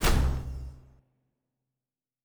Fantasy Interface Sounds
Special Click 22.wav